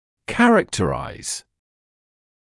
[‘kærəktəraɪz][‘кэрэктэрайз]характеризовать, описывать; изображать (British English characterise)